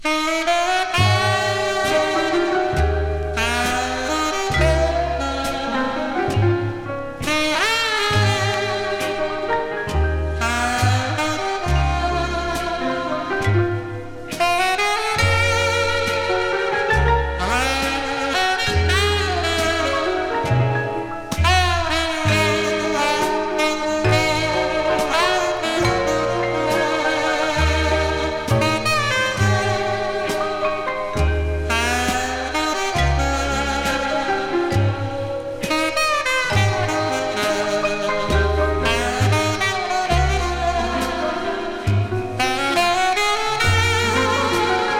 熱さも涼しも感じるような、洗練と洒落気たっぷり。
Jazz, Rhythm & Blues　USA　12inchレコード　33rpm　Stereo